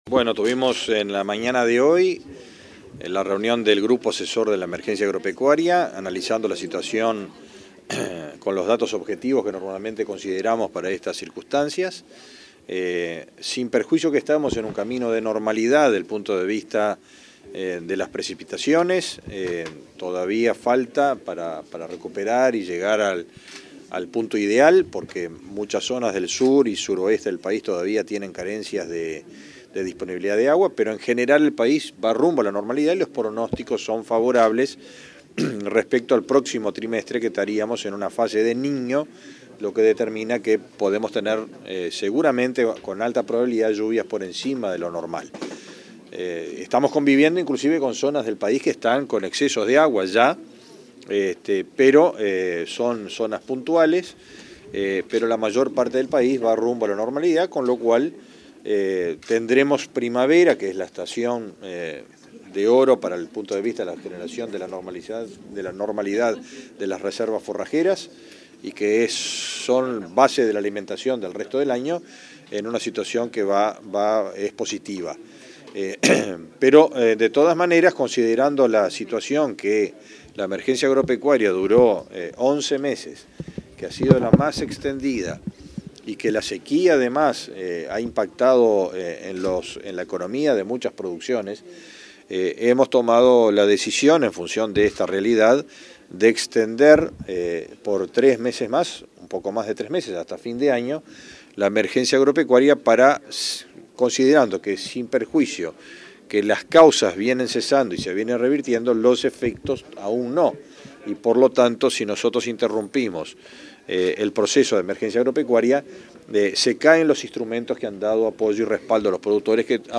Declaraciones del ministro de Ganadería, Fernando Mattos
El ministro de Ganadería, Fernando Mattos, informó a la prensa sobre la decisión del Gobierno de extender la emergencia agropecuaria.